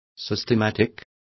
Complete with pronunciation of the translation of systematic.